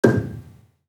Gambang-G5-f.wav